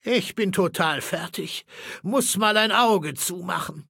Datei:Maleold01 ms06lincol ms06packagestar 000b392c.ogg
Fallout 3: Audiodialoge